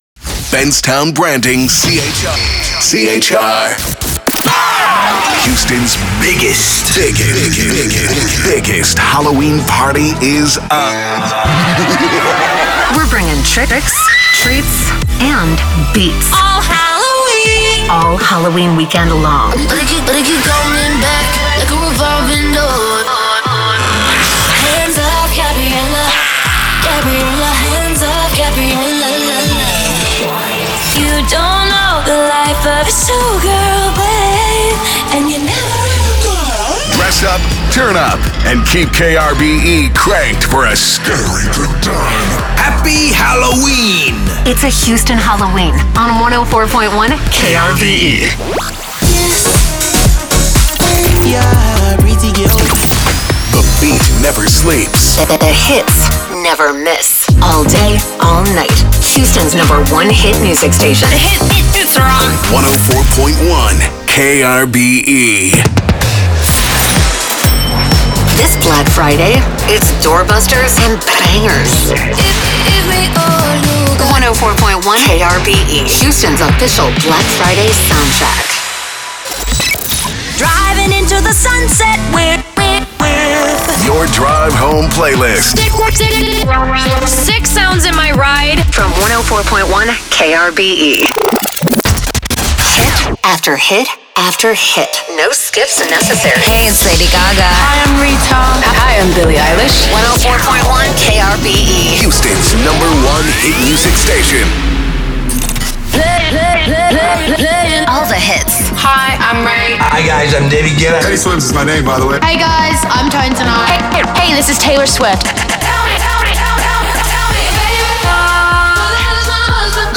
Containing shelled produced promos and sweepers, ramploops, branded song intros, artist IDs, listener drops, song hooks, musicbeds, individual imaging workparts, and more.